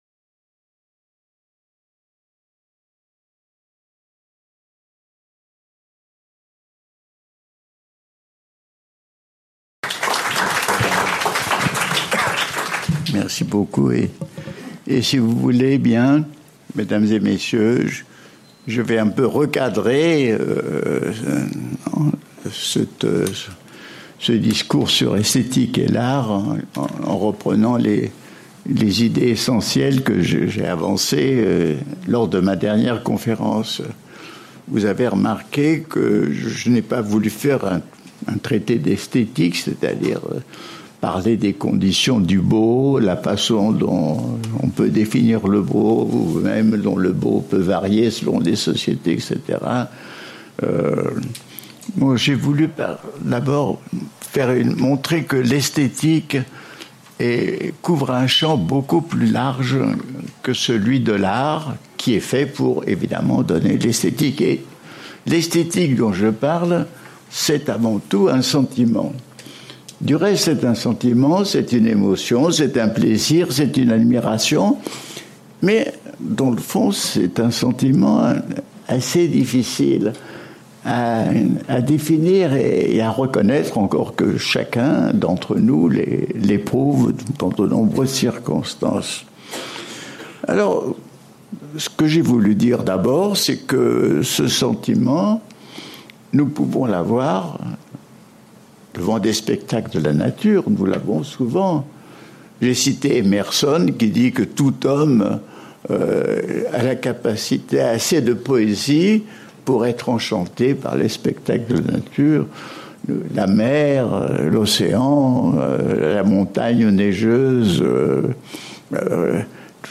Cycle de conférences Edgar Morin : Globalité et complexité (2/3) Organisé par le Collège d'études mondiales et la Fondation Calouste Gulbenkian.